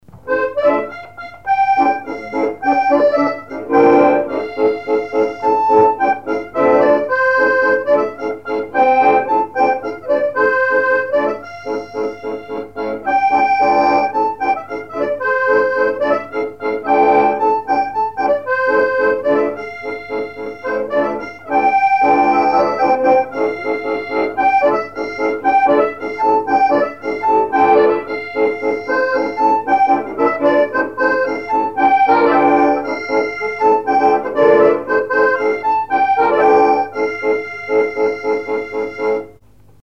danse : ronde : grand'danse
instrumentaux à l'accordéon diatonique
Pièce musicale inédite